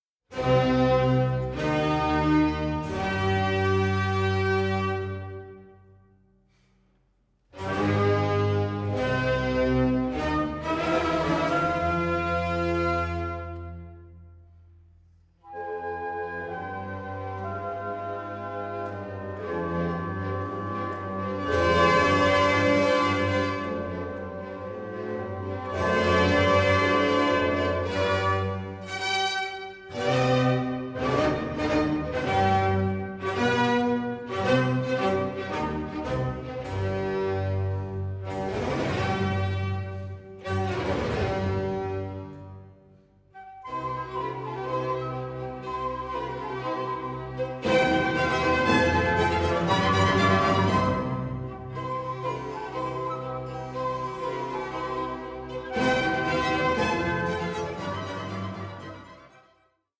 period instrument ensembles